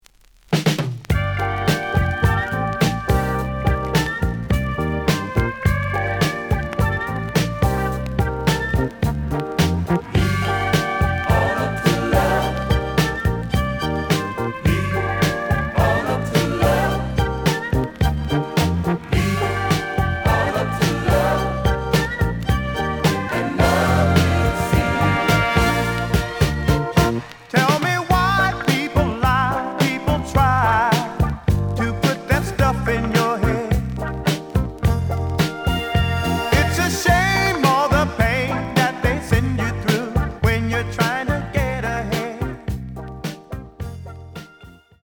The audio sample is recorded from the actual item.
●Genre: Disco
●Record Grading: VG (傷は多いが、プレイはまずまず。Plays good.)